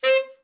TENOR SN  29.wav